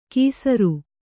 ♪ kīsaru